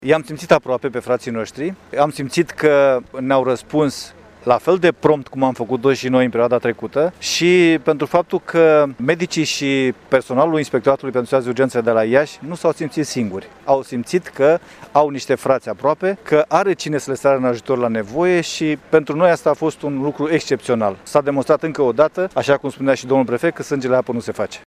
Astăzi, s-a desfăşurat, la Iaşi, ceremonia militară care a marcat sfârşitul misiunii cadrelor medicale din Republica Moldova  la Spitalul Modular de la Leţcani.
Şeful Inspectoratului General pentru Situaţii de Urgenţă din România, generalul maior Dan Iamandi, a declarat că este prima misiune concretă desfăşurată în teren de echipe integrate din România şi Republica Moldova.